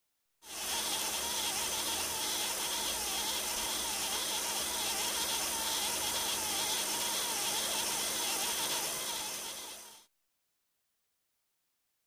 Answering Machine; Tape Background; Tape Being Wound Or Forwarded By Answering Machine; Buzz-like Whirls. Close Perspective.